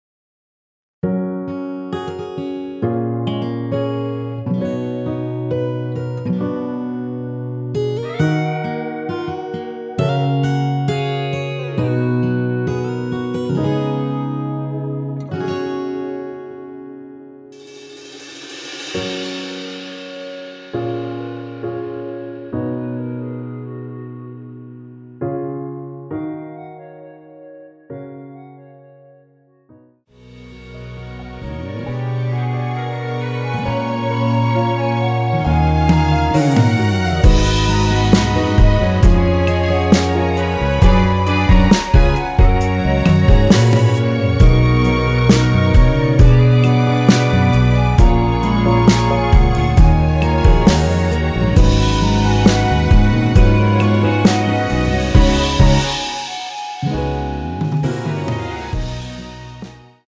원키에서(+3)올린 멜로디 포함된 MR입니다.
F#
앞부분30초, 뒷부분30초씩 편집해서 올려 드리고 있습니다.